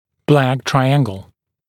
[blæk ‘traɪæŋgl][блэк ‘трайэнгл]черный треугольник